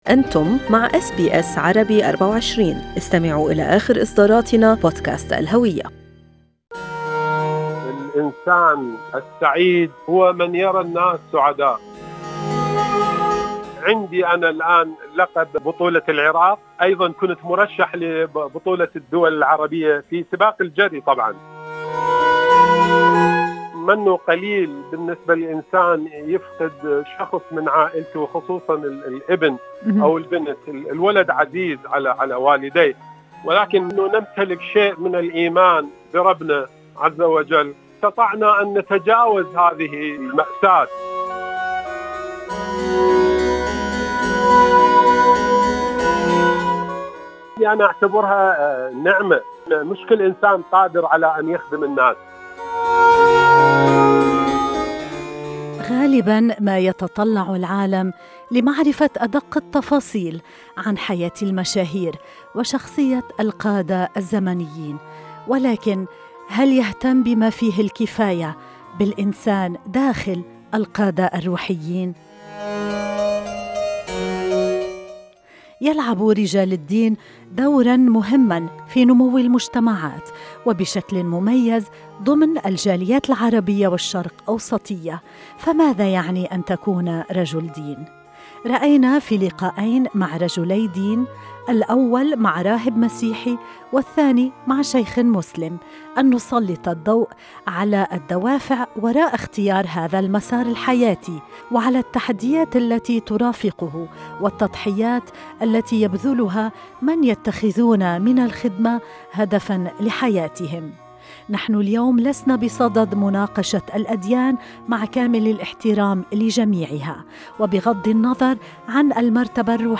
في هذا اللقاء نسلط الضوء على البعد الإنساني للقادة الروحيين، على ما يفرحهم أو يحزنهم وعلى متطلبات الخدمة والتضحية في سبيل الآخرين.